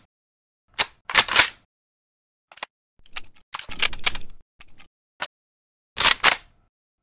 mosin_reload.ogg